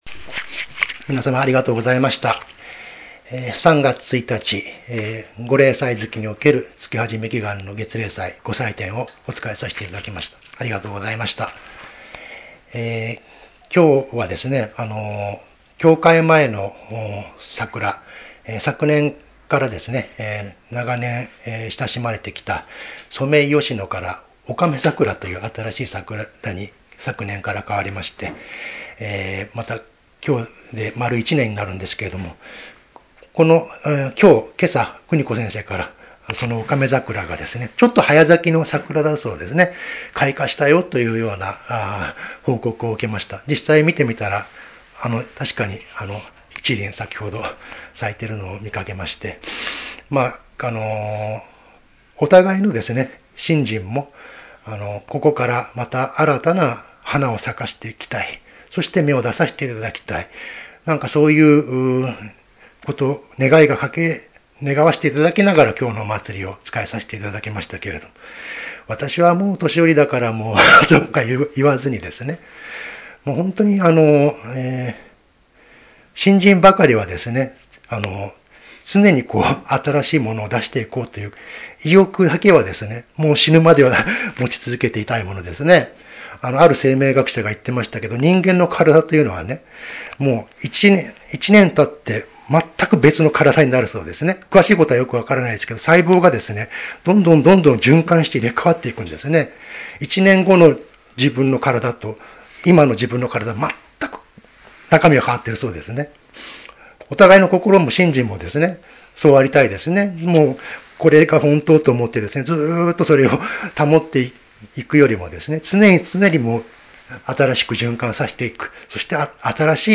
投稿ナビゲーション 過去の投稿 前 3月1日･月初め祈願月例祭